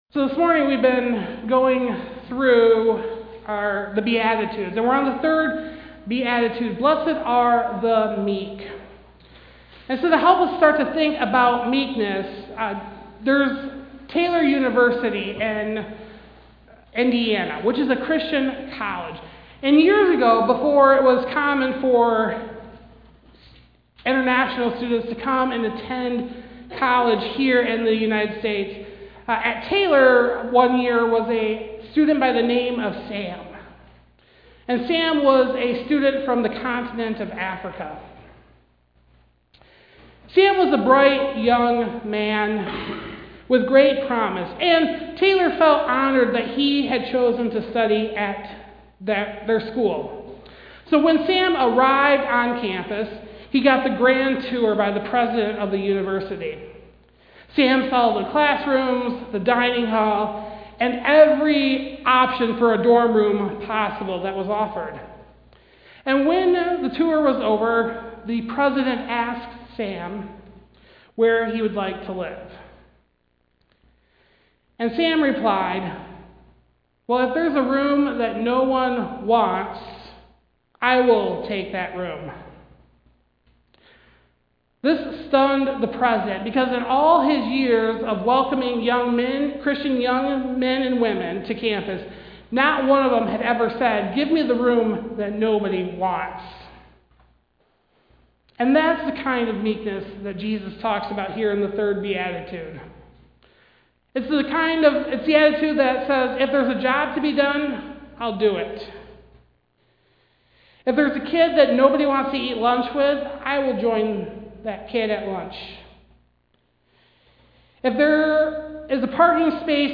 Passage: Matthew 5: 1-5; Psalm 37:1, 3-5, 7-8, 11 Service Type: Sunday Service